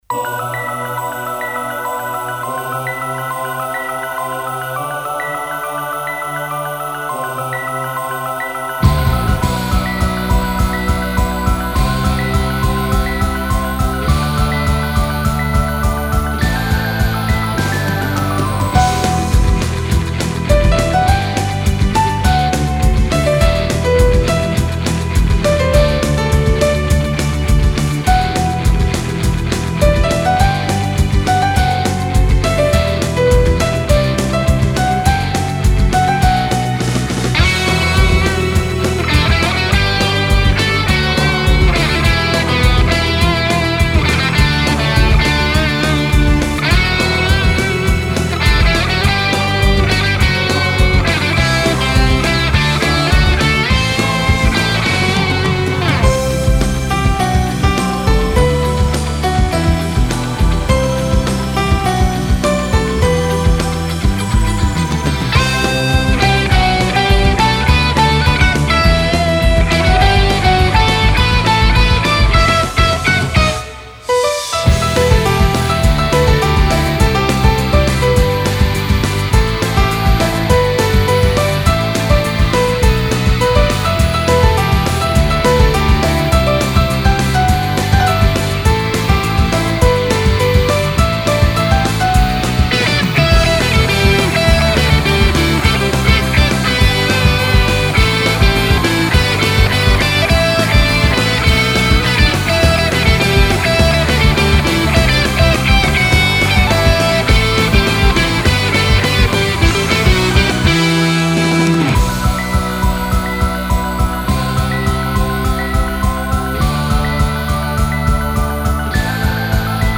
フリーBGM バトル・戦闘 バンドサウンド
フェードアウト版のmp3を、こちらのページにて無料で配布しています。